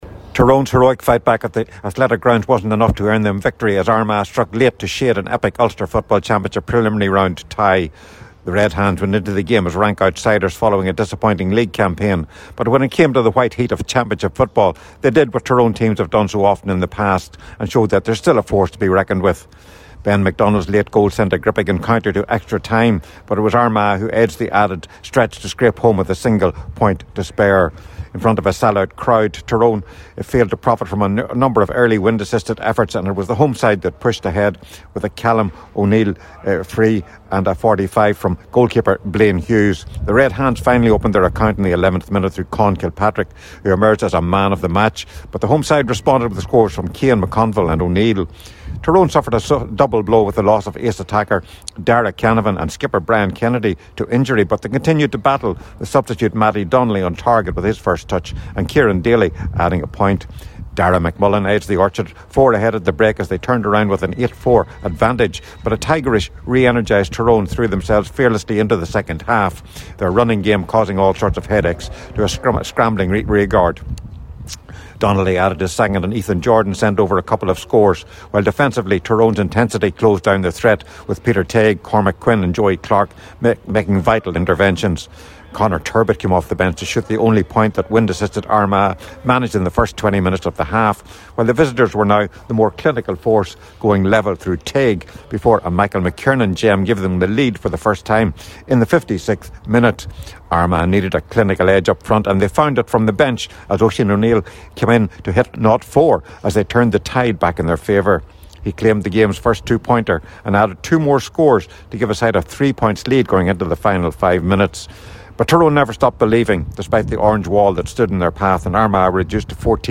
full time report